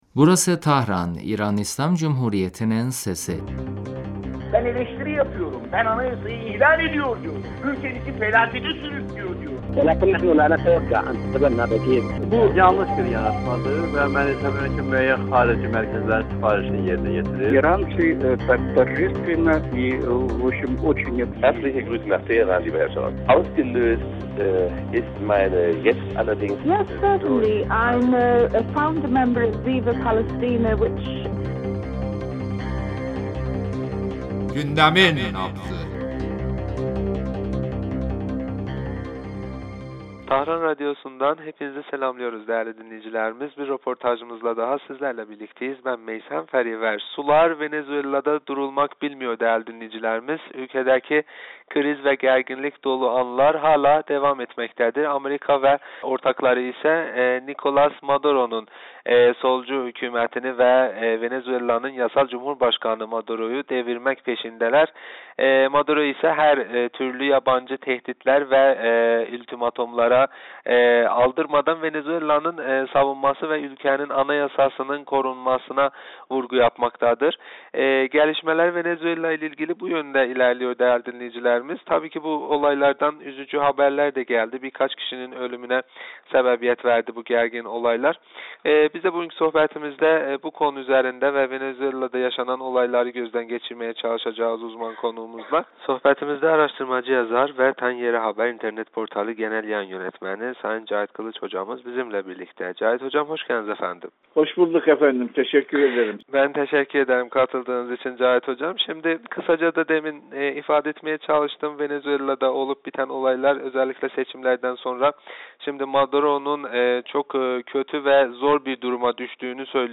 ile yaptığımız telefon görüşmesinde Venezuela'da yaşanan siyasi gerginlikler ve Amerika'nın bu ülkeye yaptığı müdahaleleri hakkında konuştuk.